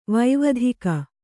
♪ vaivadhika